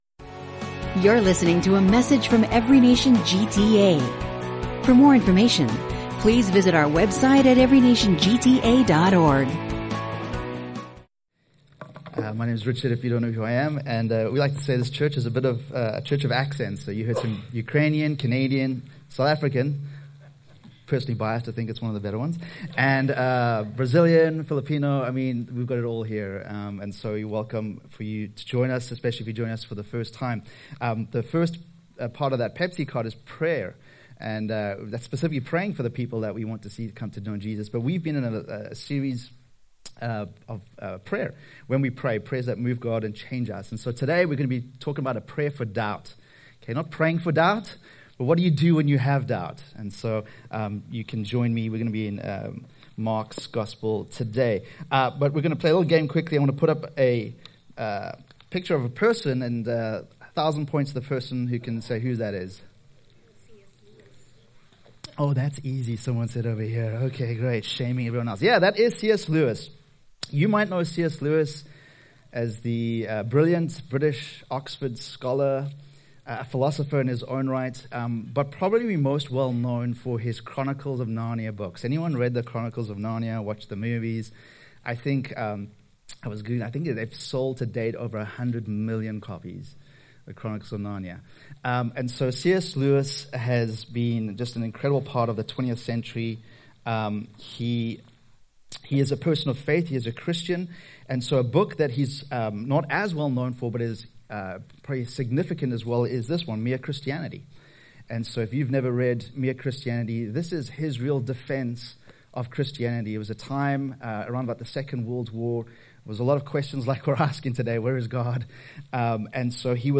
This sermon explores the relationship between faith and doubt, using the story of a grieving father in Mark 9.